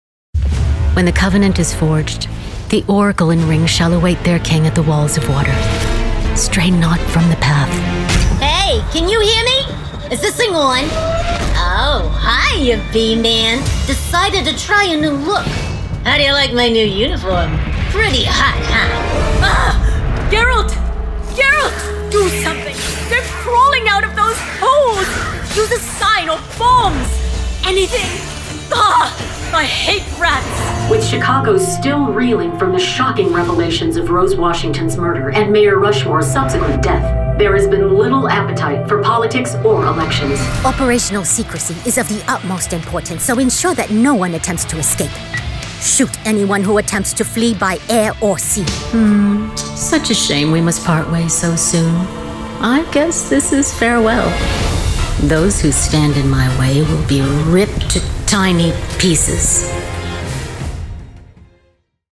Her wide range has been a blessing in the world of Commercials, Videogames and Animation and her unique characters, voices and accents captivate listeners.